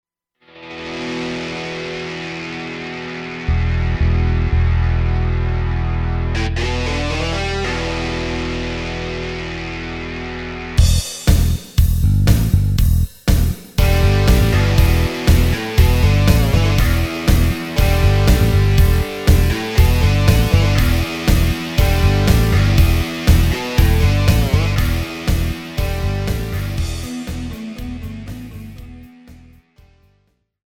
KARAOKE/FORMÁT:
Žánr: Rock